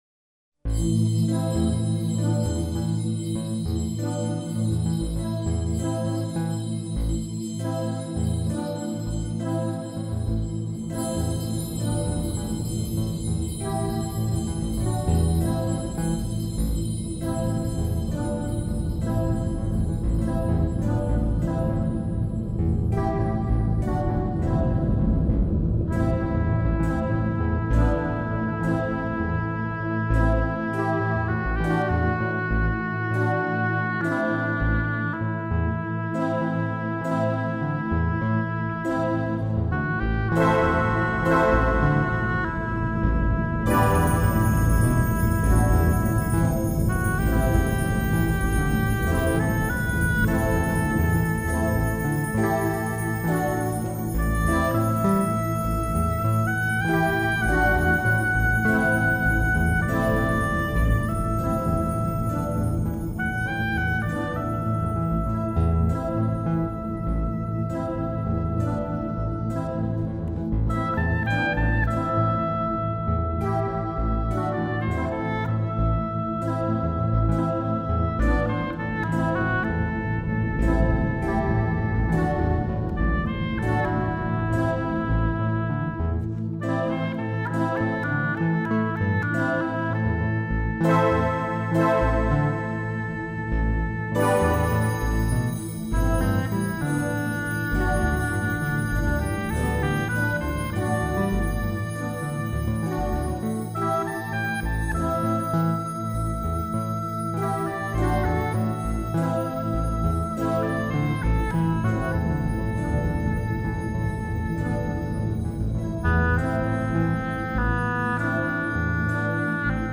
轻音乐
纯静，和谐，与世无争，就像一个泛音。